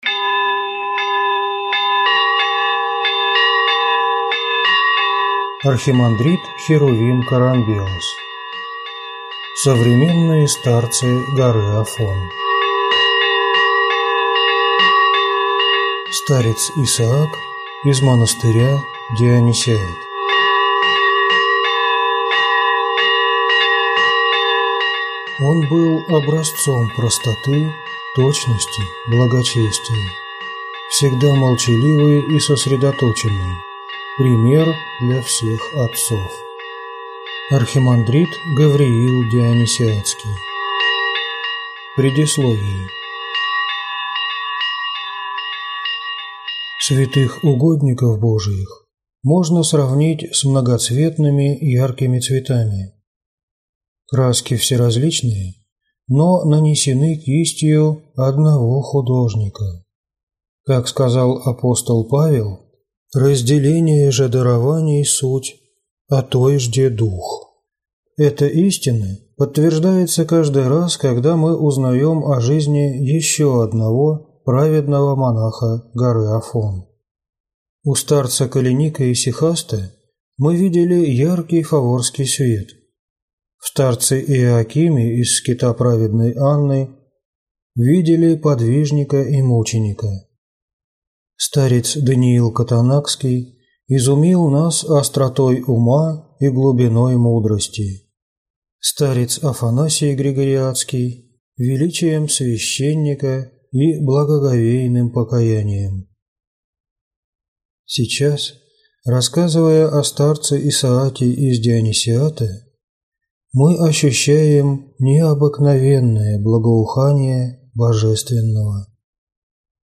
Аудиокнига Старец Исаак из монастыря Дионисиат | Библиотека аудиокниг